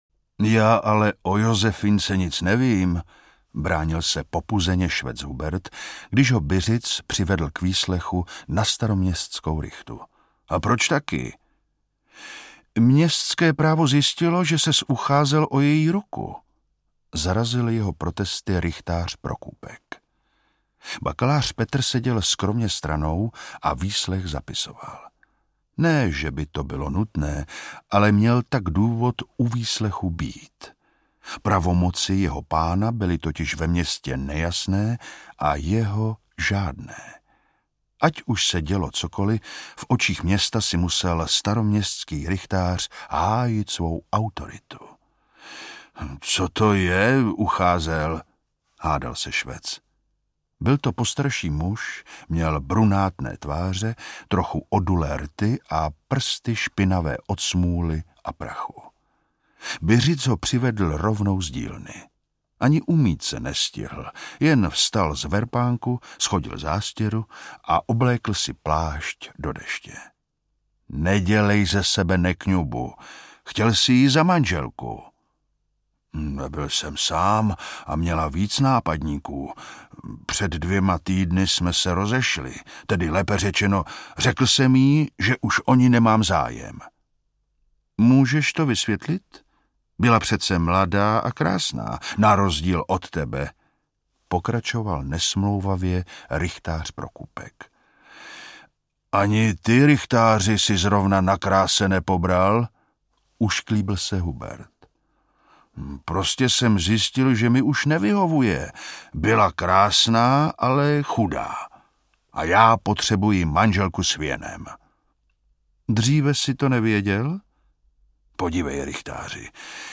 Zásnubní prsten audiokniha
Ukázka z knihy
Vyrobilo studio Soundguru.
zasnubni-prsten-audiokniha